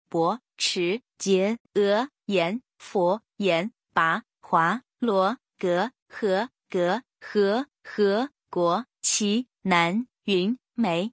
第二声の漢字と発音